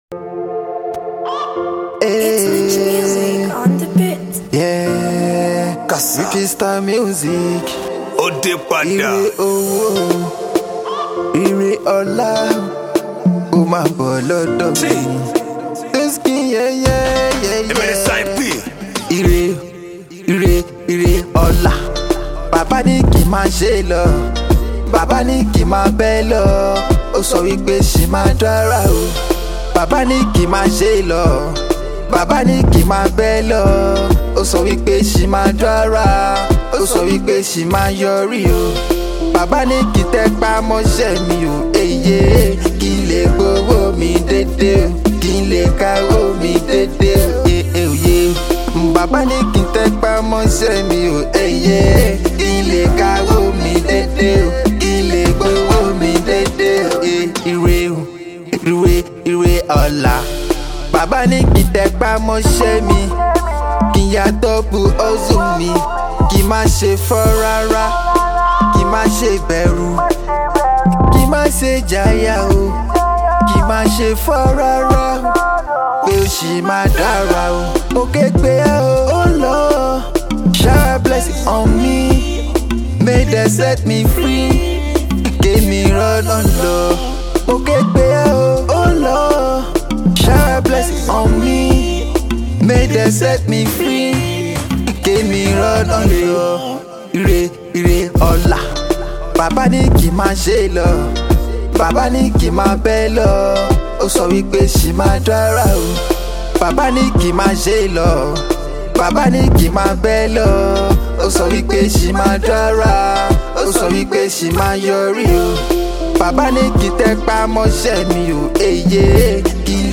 MyMp3Tracks > Urban Mp3 - 4.5Mo - T : 13 fois - E : 0 fois.